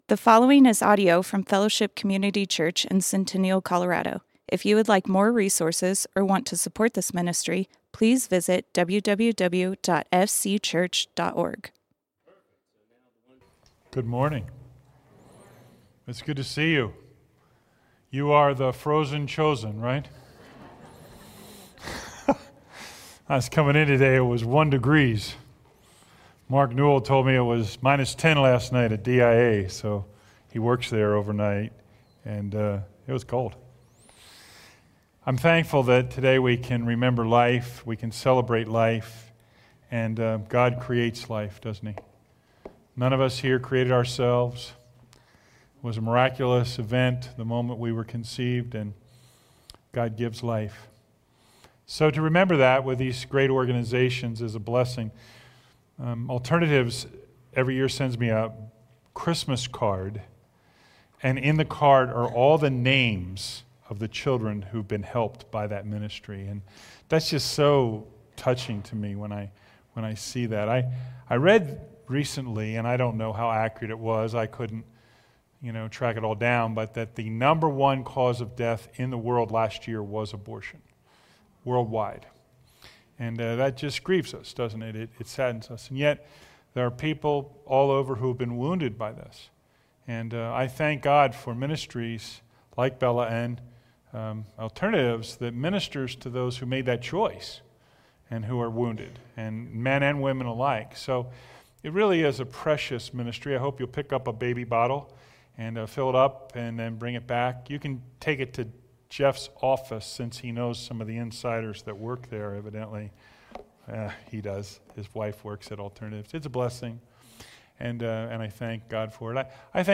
Fellowship Community Church - Sermons The Great Tribulation Play Episode Pause Episode Mute/Unmute Episode Rewind 10 Seconds 1x Fast Forward 30 seconds 00:00 / 35:42 Subscribe Share RSS Feed Share Link Embed